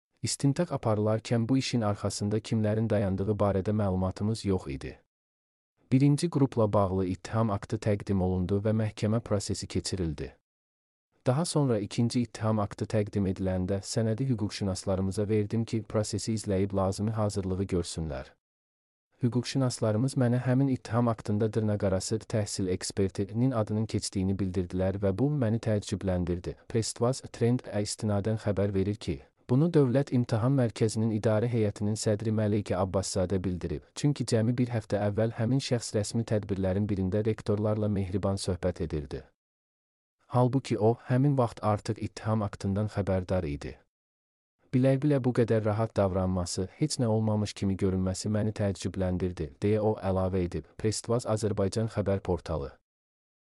mp3-output-ttsfreedotcom-92.mp3